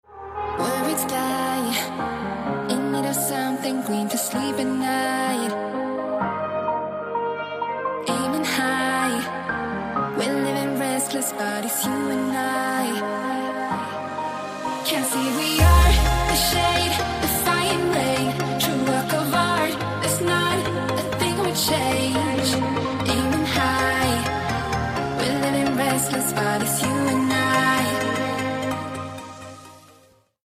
• Качество: 256, Stereo
поп
женский вокал
dance
EDM
club
Melodic
romantic
vocal